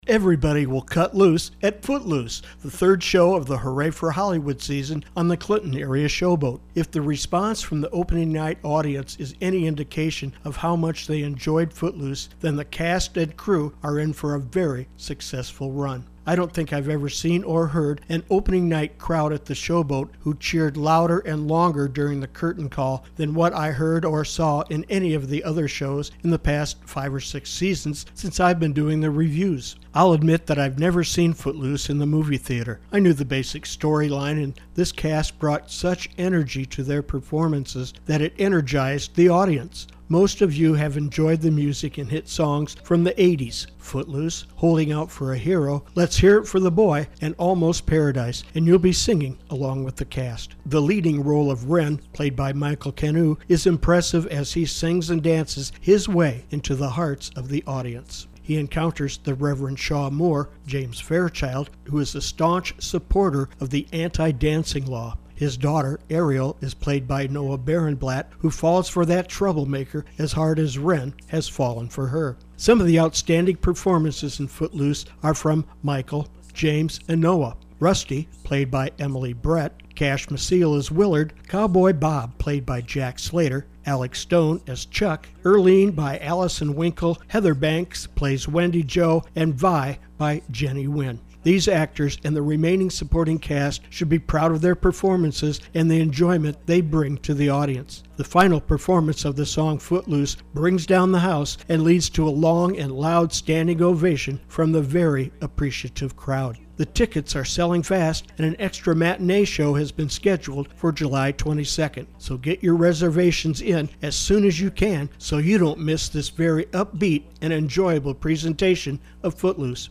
footloose-review-7-6-17.mp3